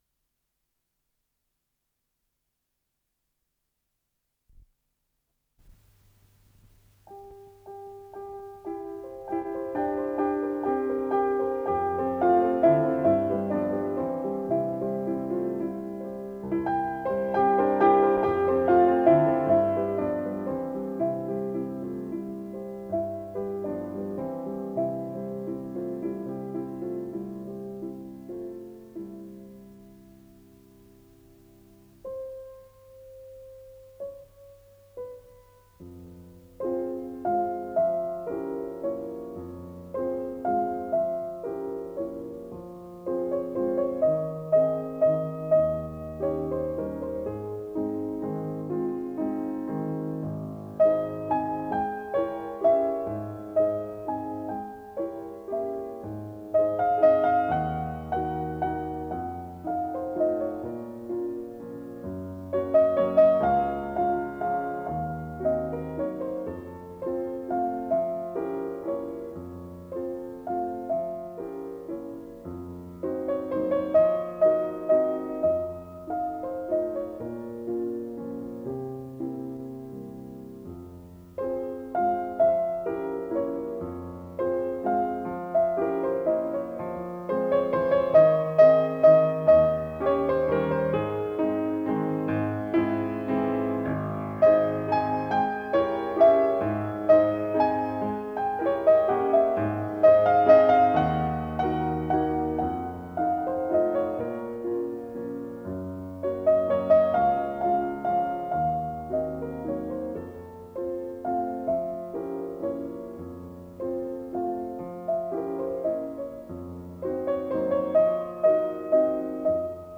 фортепиано
Для фортепиано, фа минор